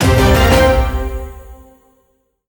build_blueprint.wav